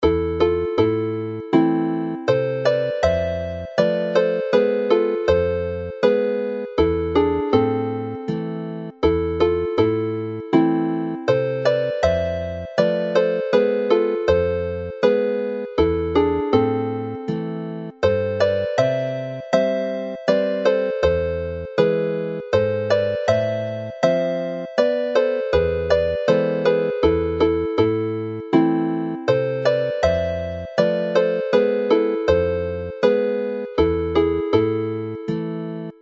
Play the melody with harmony